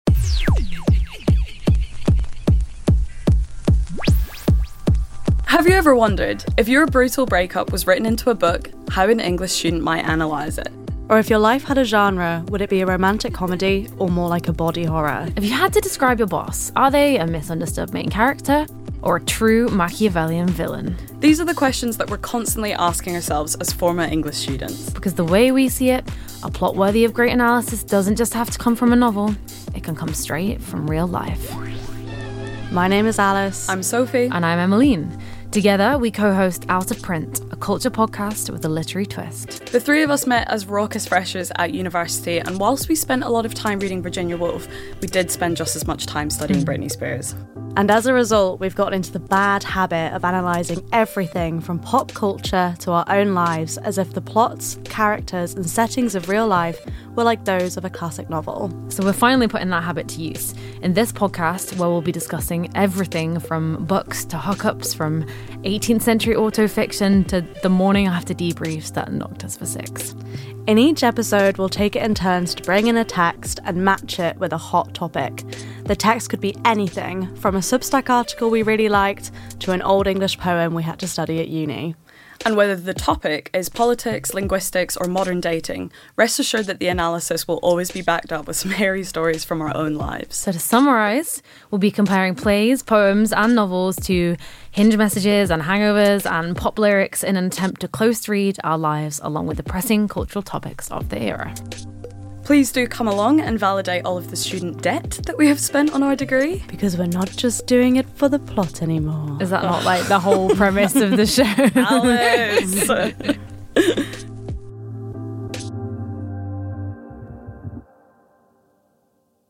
Out-of-Print is a cultural commentary podcast with a literary twist, where real life best friends and former English students break down the texts and topics most relevant to the highs and lows of early adulthood.